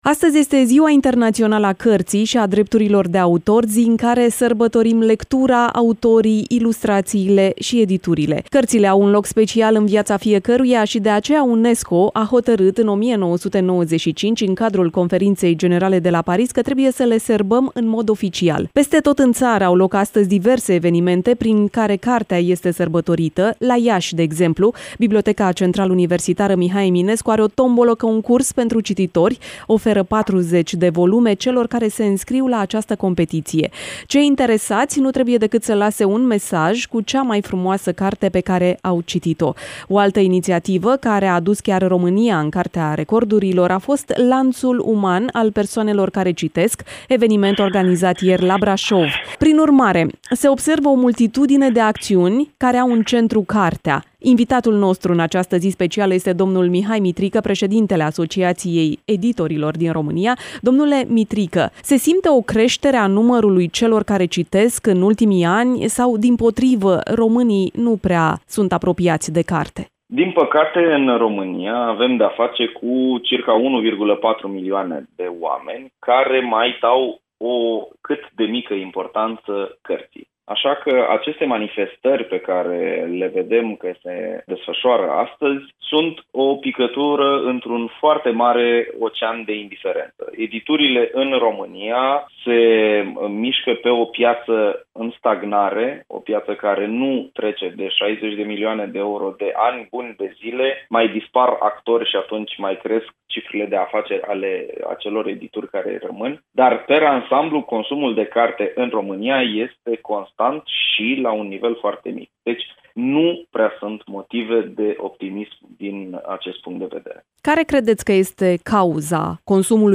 Mai jos, varianta audio a interviului: Redactor